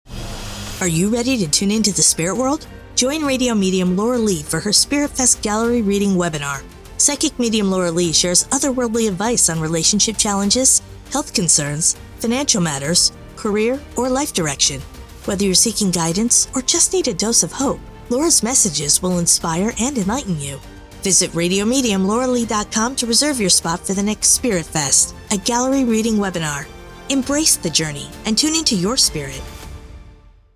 American English (Neutral), American English (Southern)